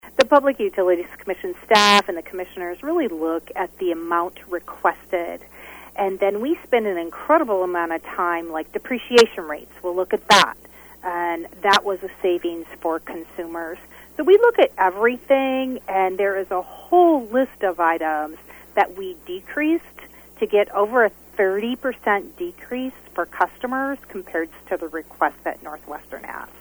PUC chairperson Kristie Fiegen explains some of the factors in the rate increase.